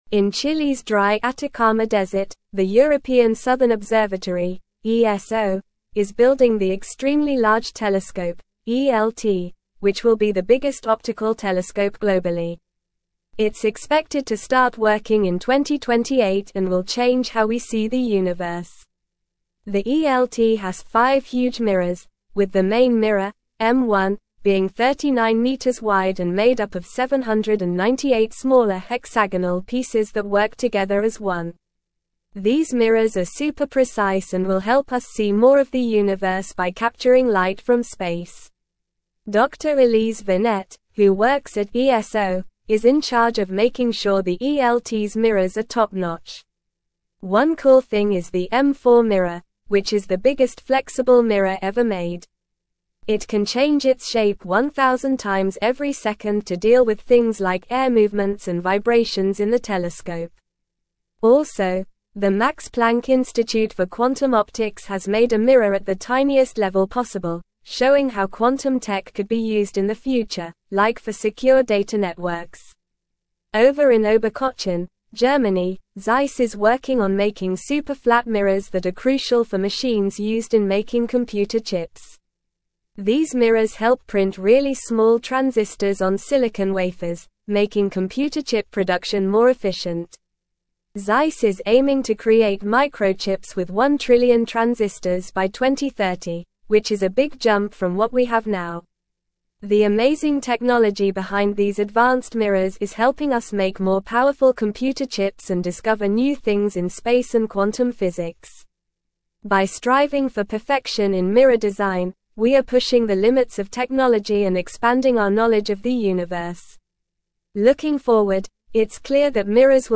Normal
English-Newsroom-Upper-Intermediate-NORMAL-Reading-Advancing-Technology-Through-Precision-The-Power-of-Mirrors.mp3